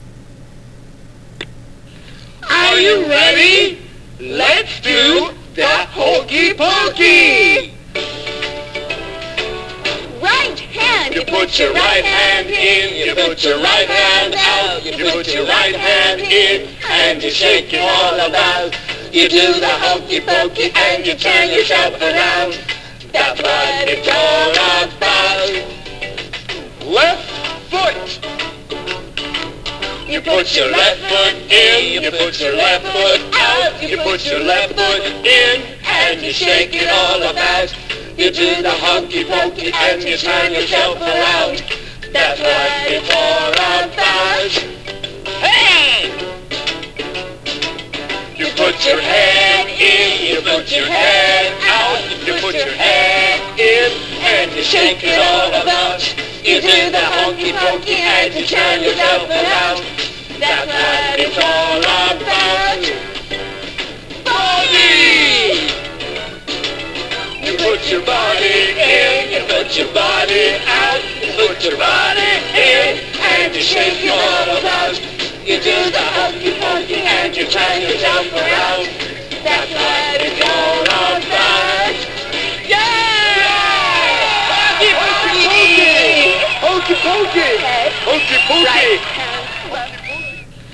歌